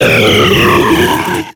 Cri de Regice dans Pokémon X et Y.